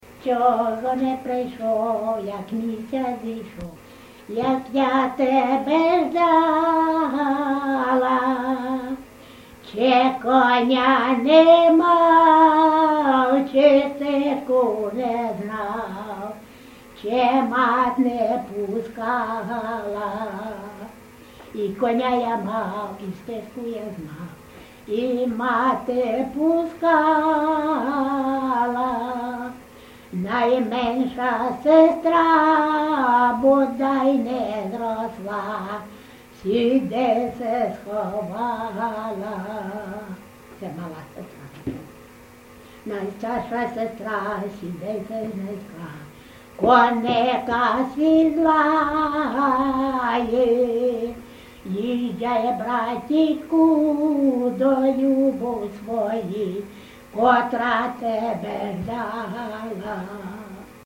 ЖанрПісні з особистого та родинного життя
Місце записум. Сіверськ, Артемівський (Бахмутський) район, Донецька обл., Україна, Слобожанщина